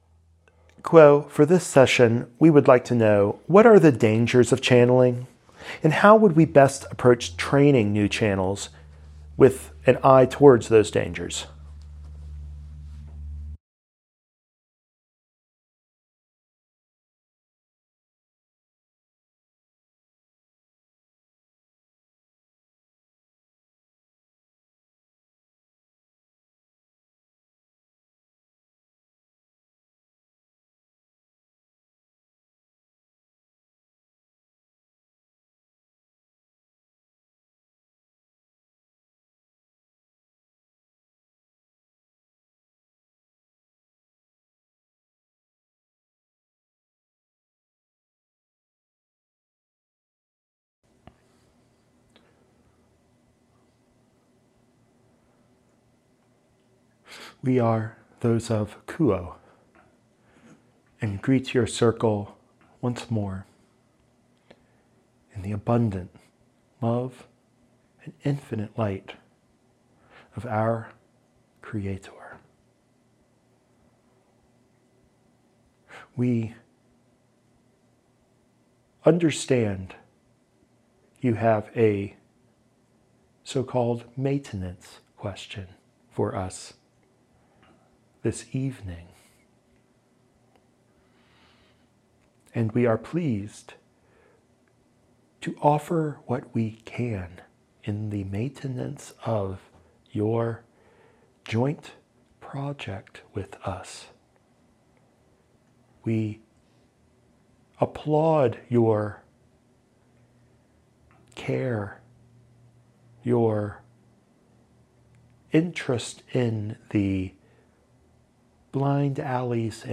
This evening session features those of Q’uo discussing the pitfalls of channeling with a special focus on channeling instruction.